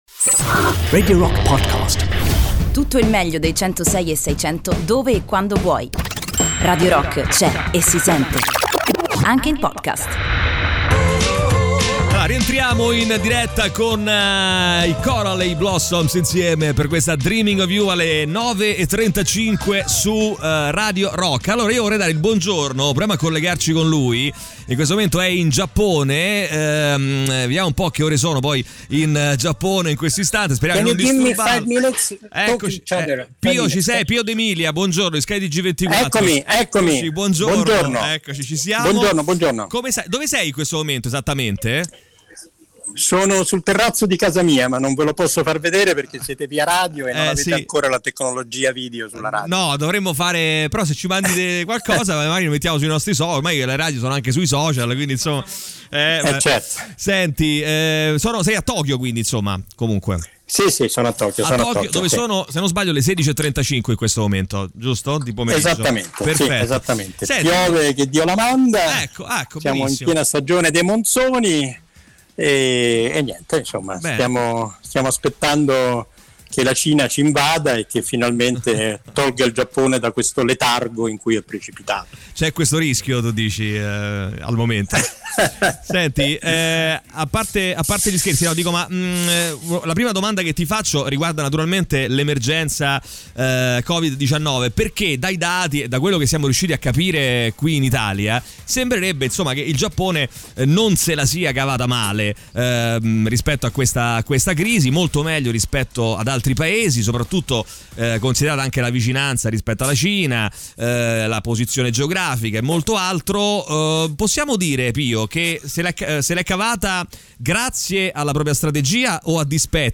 Intervista: "Pio D'Emilia - Sky TG24" (22-06-20)
in colegamento telefonico